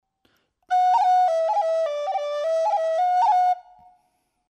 Dobre picado dedo
Pito Galego